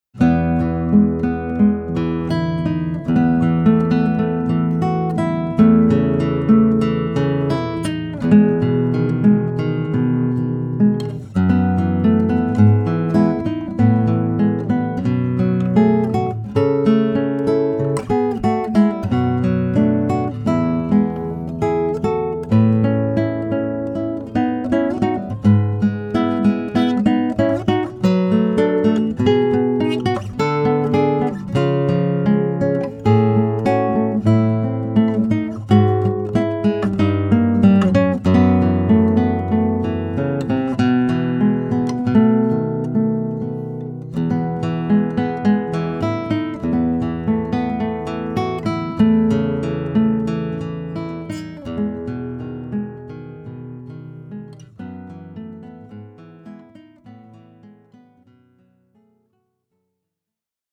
Audio Examples (solo guitar)
Spanish, Flamenco, Latin American, Romantic